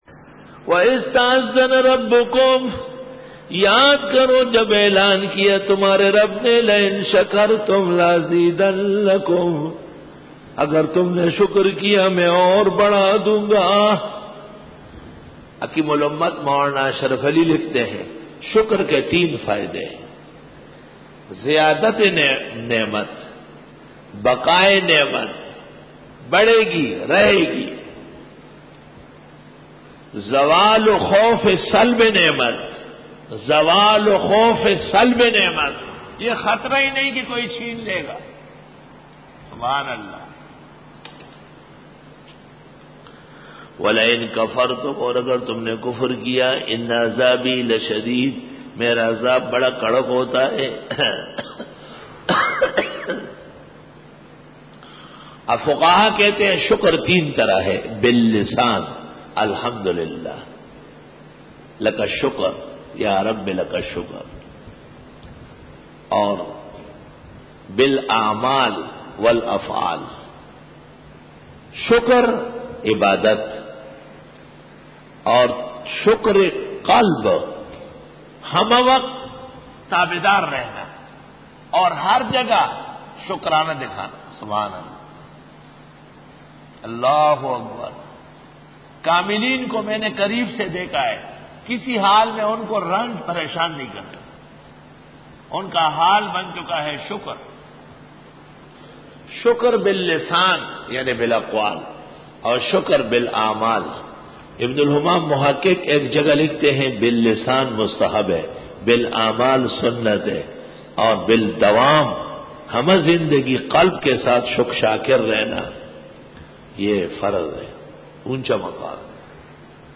سورۃ ابراھیم رکوع-02 Bayan